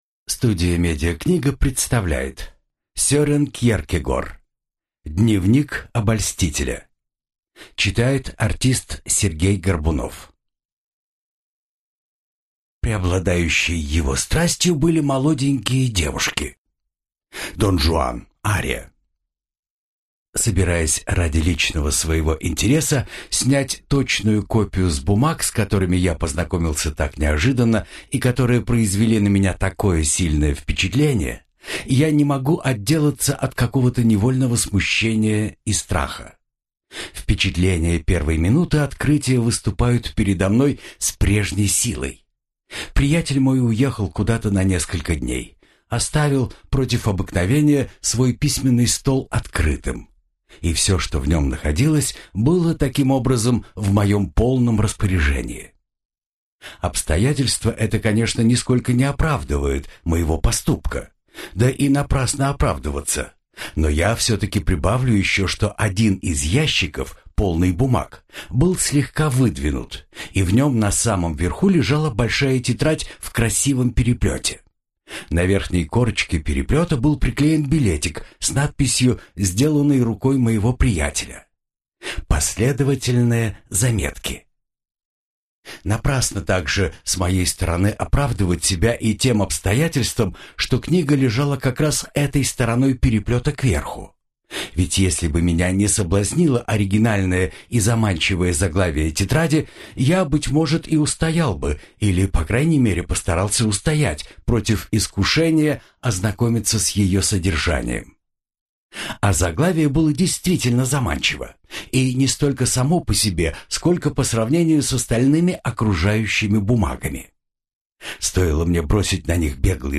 Аудиокнига Дневник обольстителя | Библиотека аудиокниг